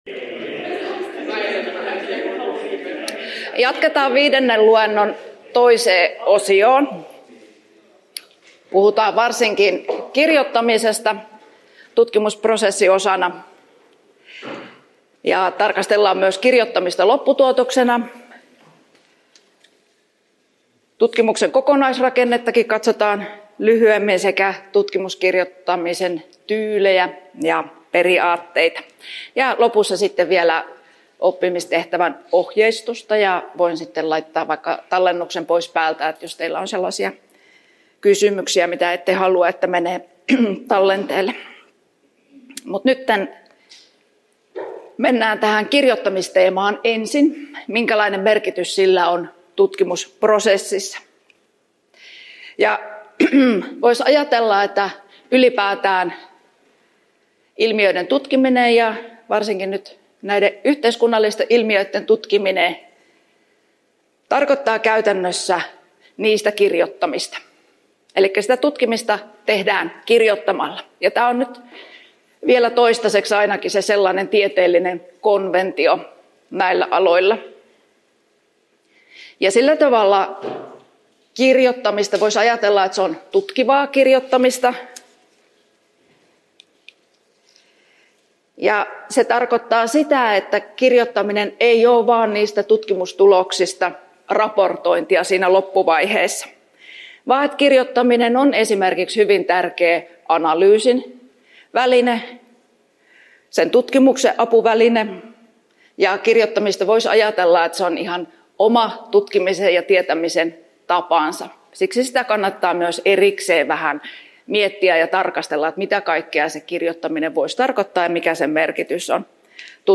5. luento osa B (1.10.2024) — Moniviestin